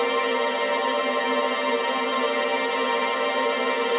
GS_TremString-C7.wav